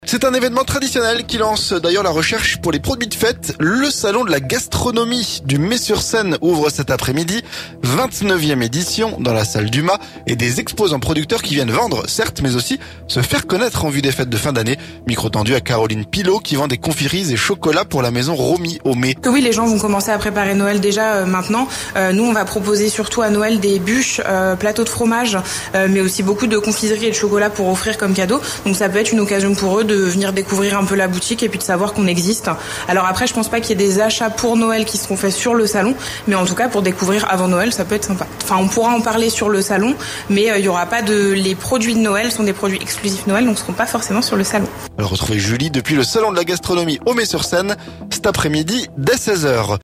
Micro tendu